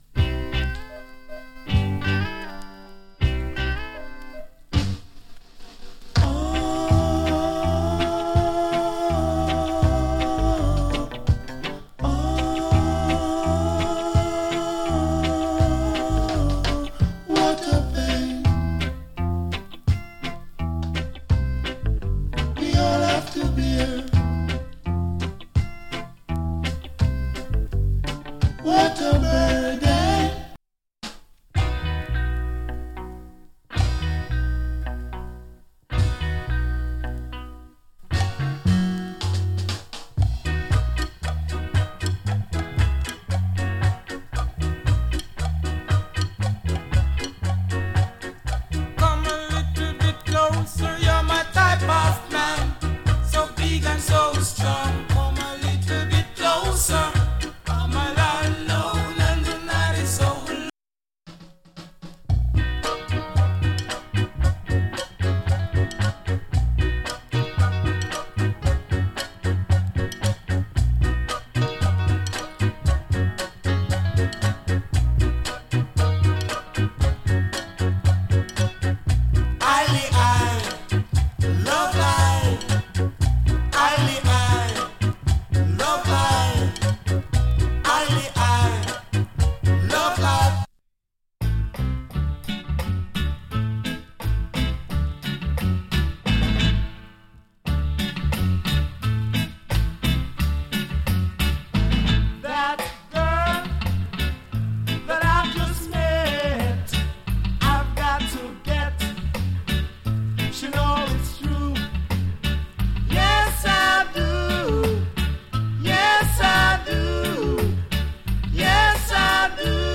チリ、パチノイズ少し有り。
EARLY REGGAE 〜 REGGAE